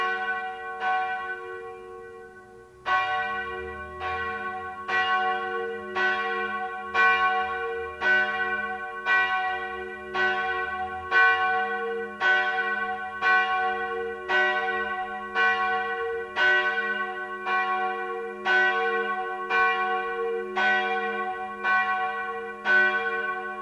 Marien-Glocke
Marienglocke.mp3